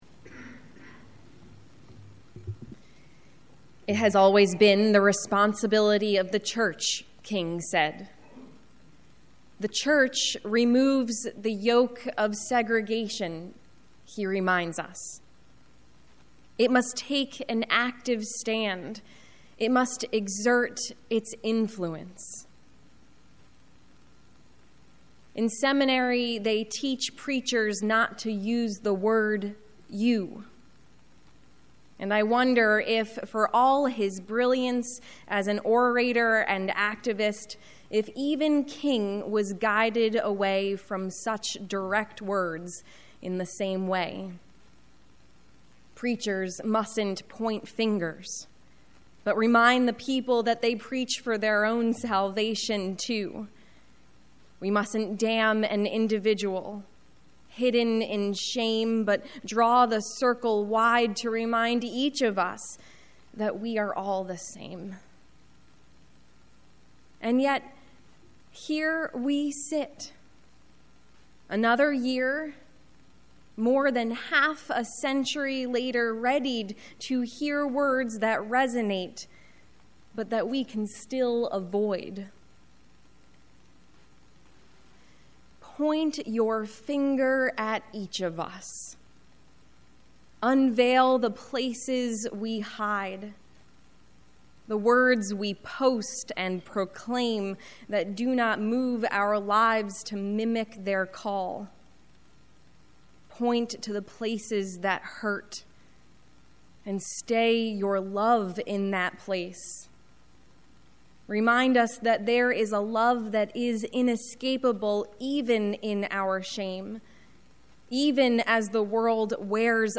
prayer-1.20.19.mp3